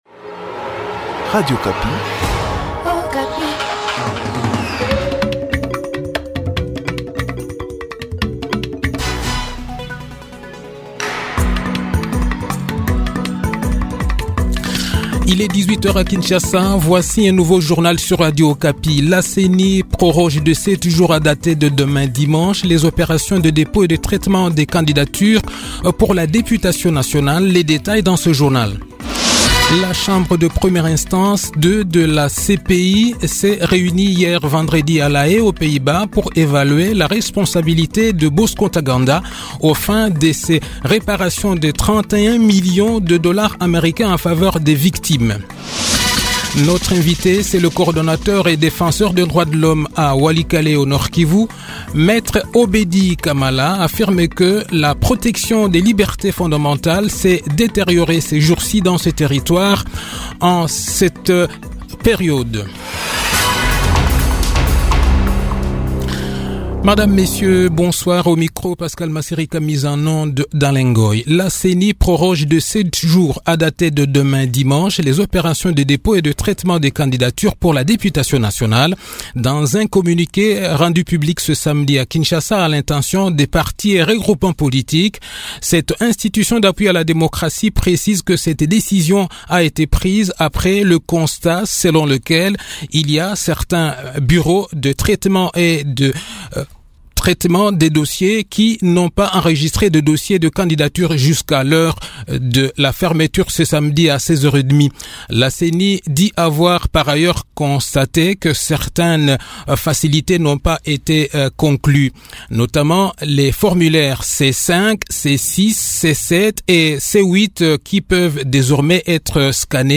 Le journal de 18 h, 15 Juillet 2023